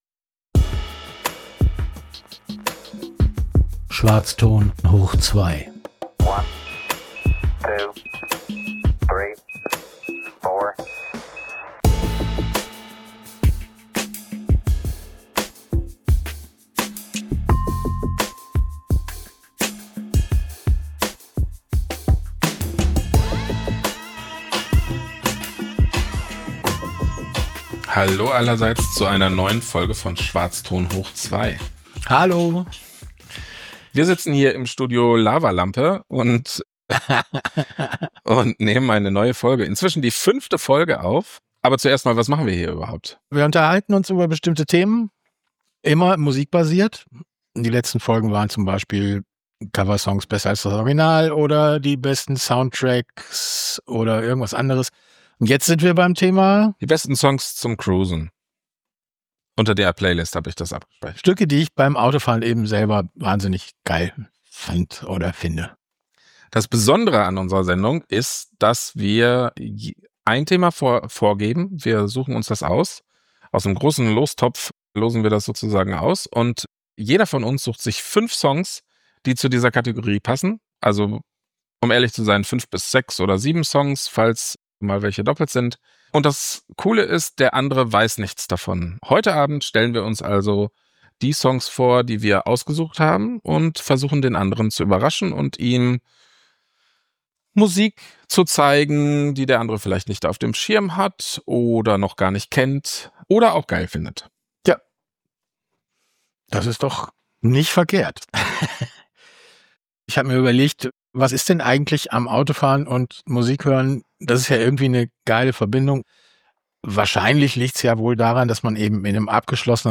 ShowNotes: Willkommen zur fünften Runde Schwarzton hoch 2 aus dem Studio Lavalampe!